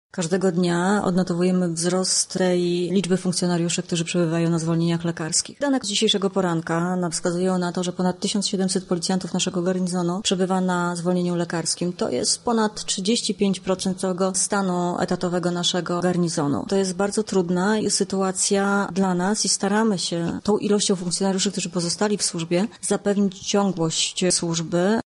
Od piątego listopada liczba policjantów na zwolnieniach lekarskich rośnie – mówi nadkomisarz